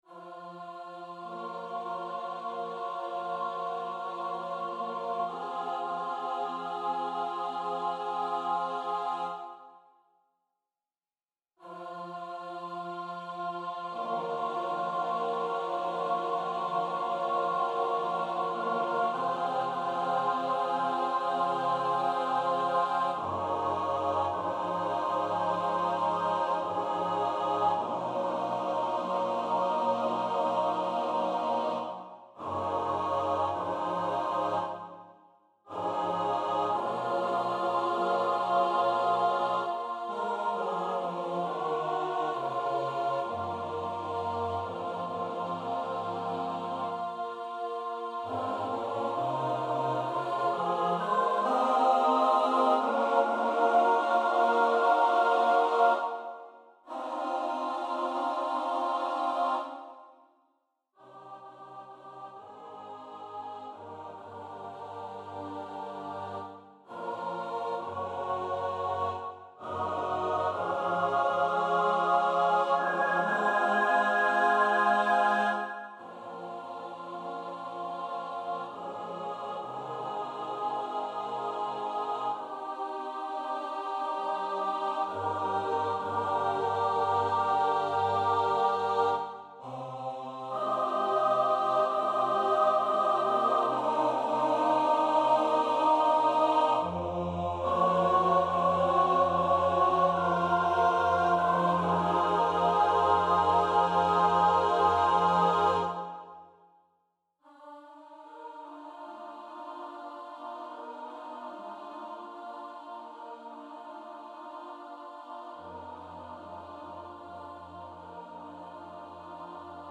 SSAATTBB, a cappella